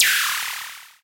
Play, download and share Analog Chirp original sound button!!!!
openhat-analog.mp3